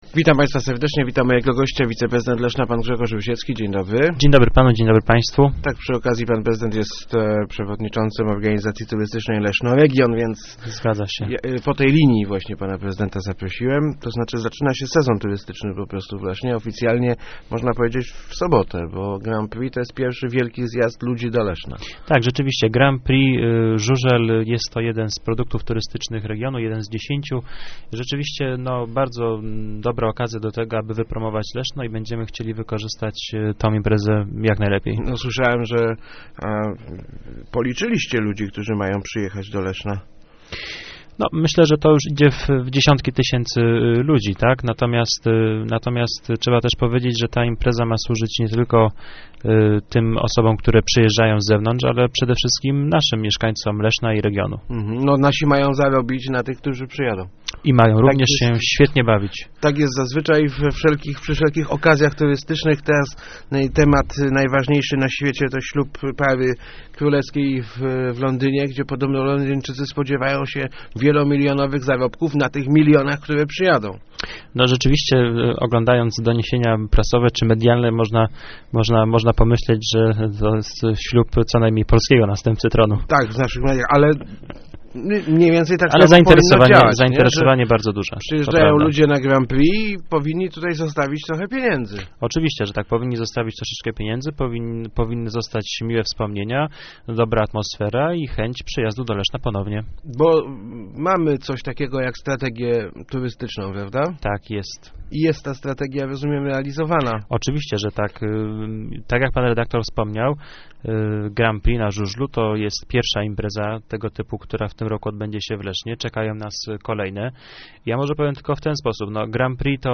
Grand Prix na �u�lu oraz Piknik Szybowcowy to dwa najwi�ksze turystyczne "przeboje" Leszna - mówi� w Rozmowach Elki wiceprezydent Grzegorz Rusiecki.